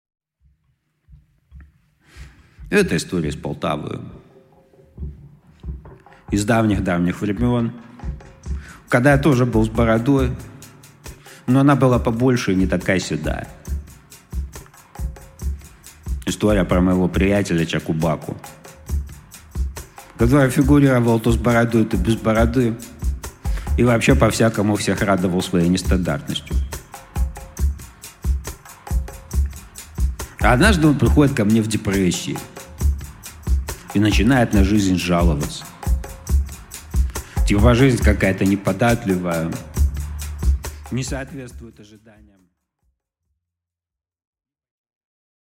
Аудиокнига Про Чаку-Баку | Библиотека аудиокниг
Прослушать и бесплатно скачать фрагмент аудиокниги